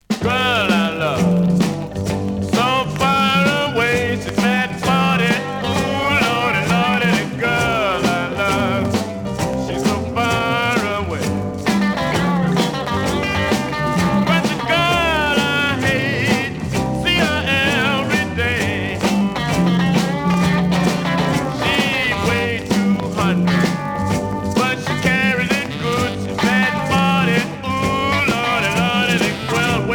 Beat rock blues